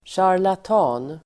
Ladda ner uttalet
charlatan.mp3